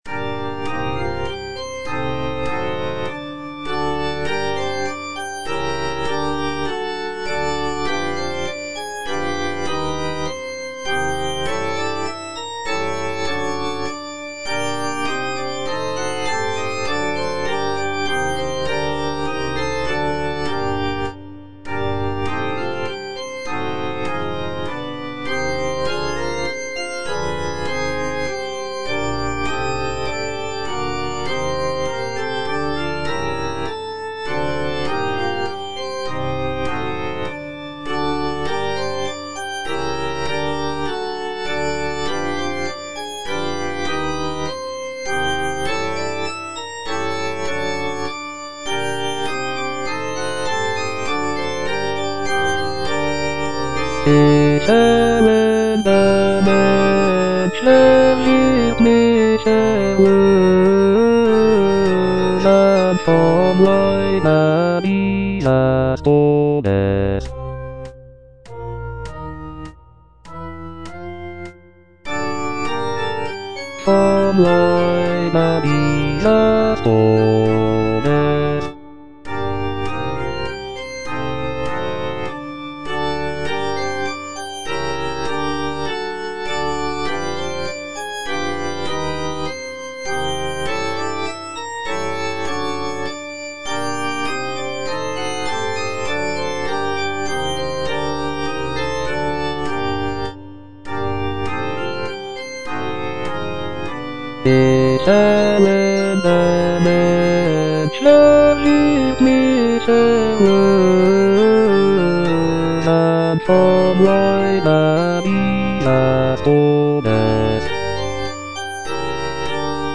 Cantata
Bass (Voice with metronome) Ads stop